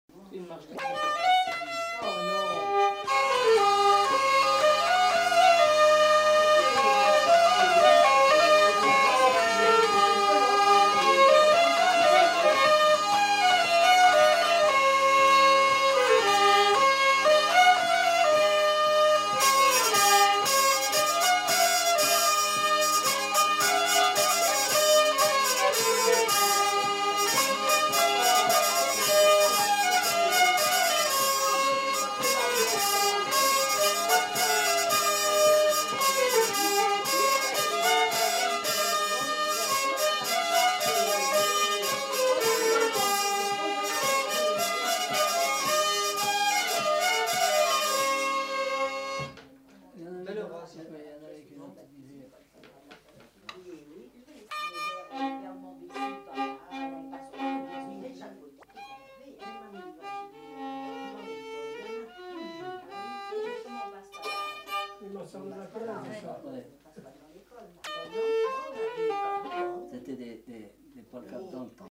Marche